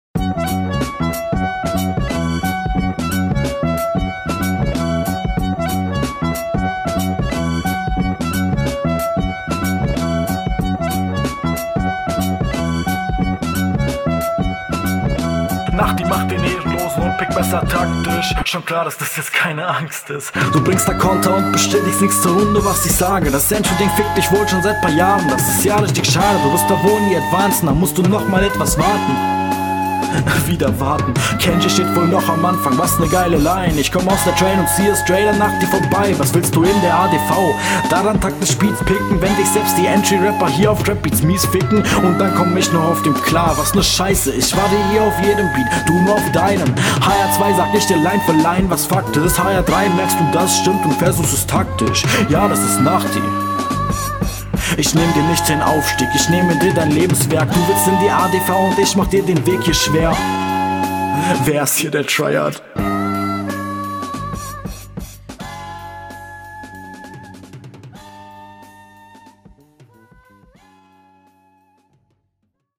sound wieder komisch. weiß nicht, das klingt einfach komisch gemixed. lines sind ok. ist wieder …
Die danach Klingt gut, stimme etwas zu laut.